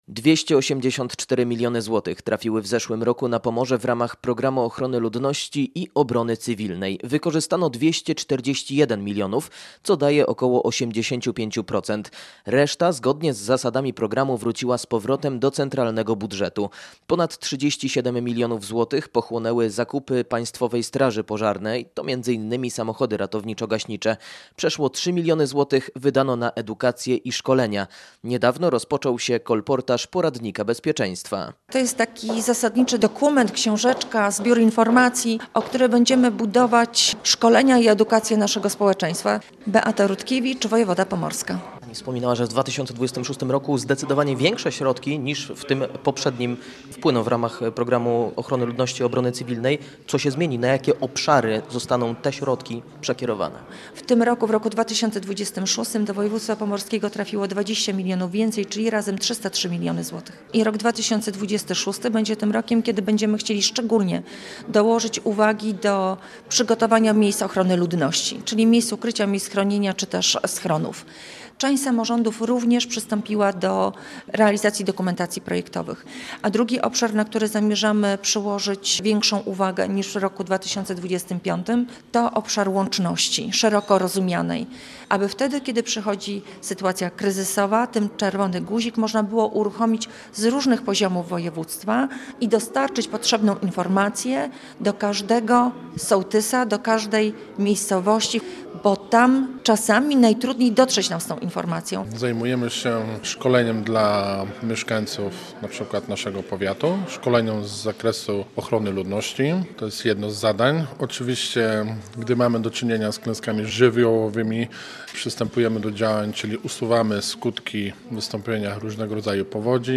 Konferencja dotycząca bezpieczeństwa odbyła się w Muzeum Zamkowym w Malborku.